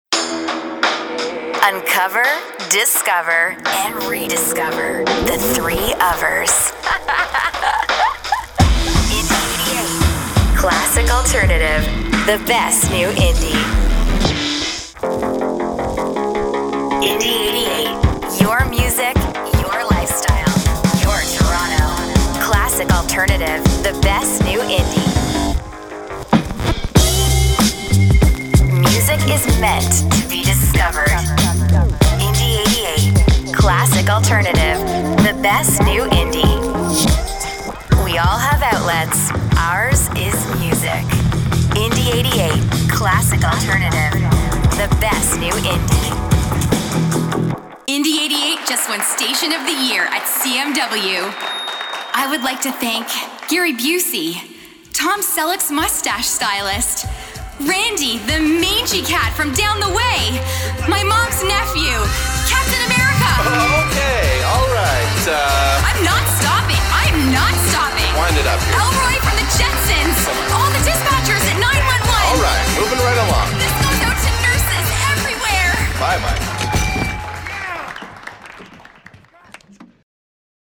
Female
I’m a Canadian voiceover artist with a native North American accent and a warm, youthful, relatable sound.
Microphone: Rode NT1-A